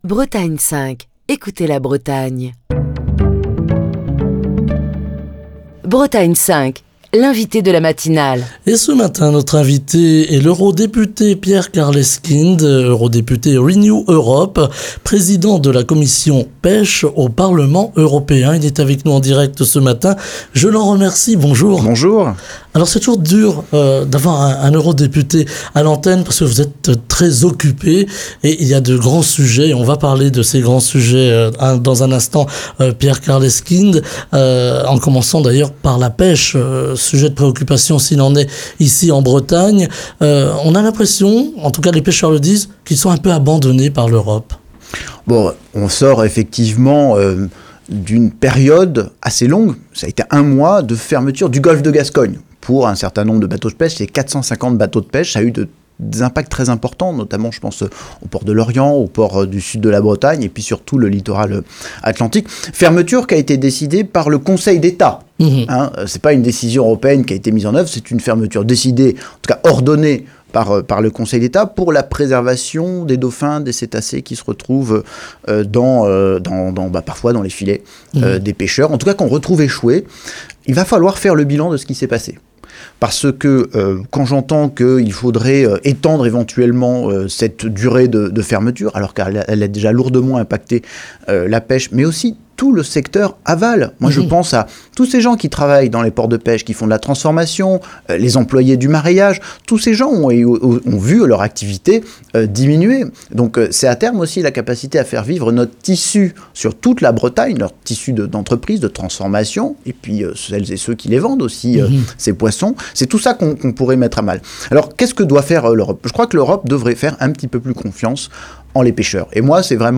Ce matin, Pierre Karleskind, eurodéputé Renew Europe, président de la Commission PECH du Parlement européen est l'invité de Bretagne 5 Matin, pour évoquer la crise de la pêche et la situation difficile des pêcheurs, la colère des agriculteurs qui ont lancé de nouvelles actions, la préparation des élections européennes avec la montée de l'extrême droite dans les intentions de vote, la difficulté de l'UE sur la gestion les flux migratoires, la mort de l'opposant russe Alexeï Navalny, les tensions entre la Russie et l'Europe avec une situation géopolitique en pleine r